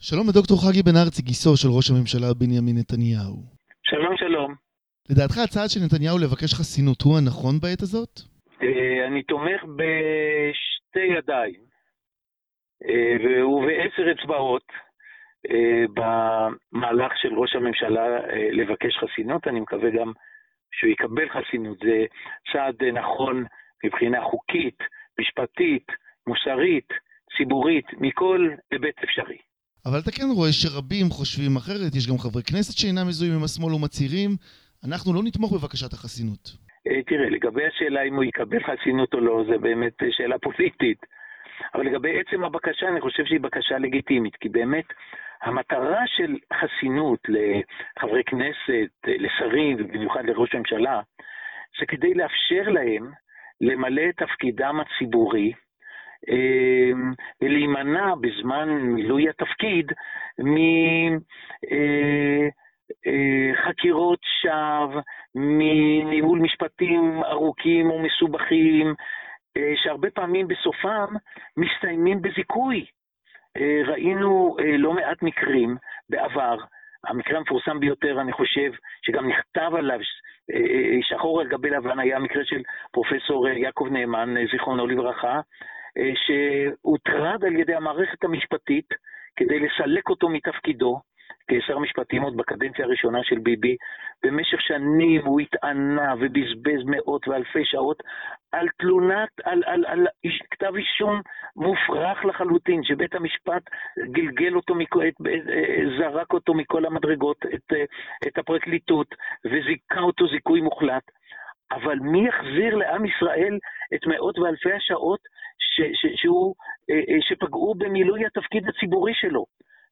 Это – законный, юридически, морально-соответствующий шаг», - сказал он в интервью корреспонденту 7 канала.